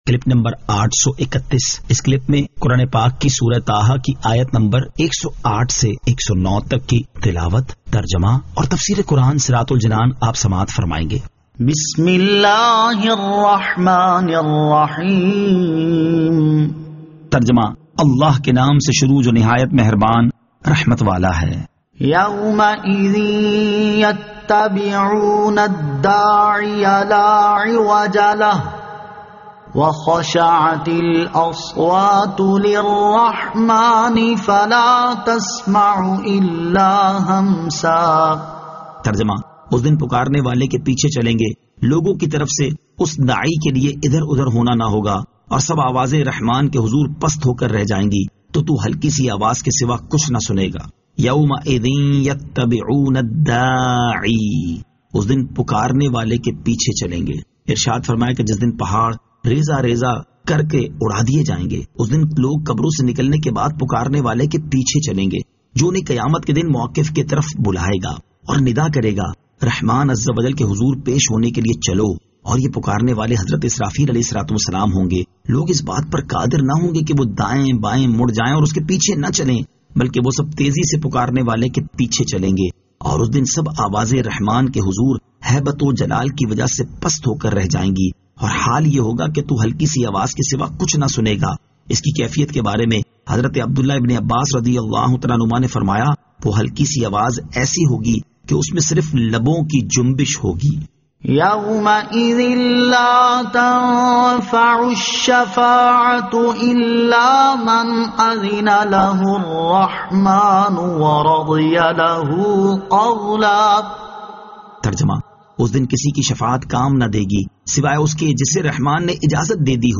Surah Taha Ayat 108 To 109 Tilawat , Tarjama , Tafseer